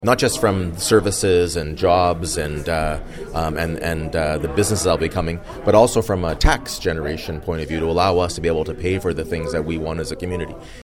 Mayor Mitch Panciuk tells Quinte News how important it is for the city.